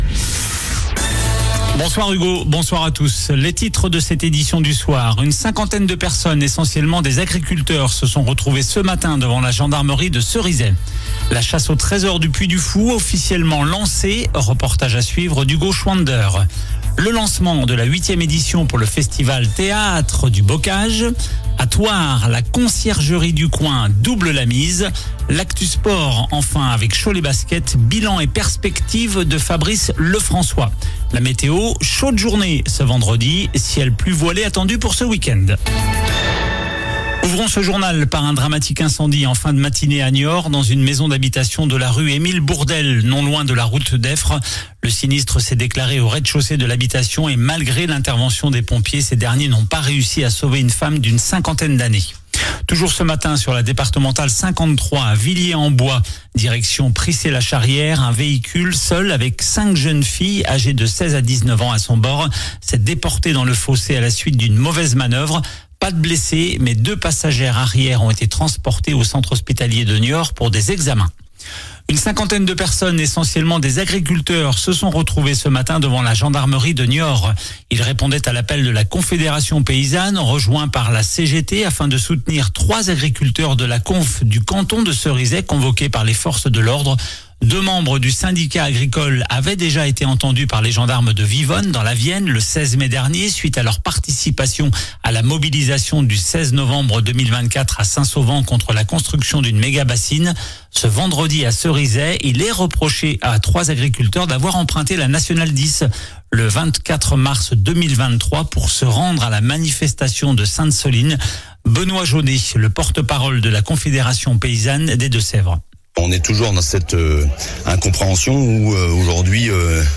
JOURNAL DU VENDREDI 30 MAI ( SOIR )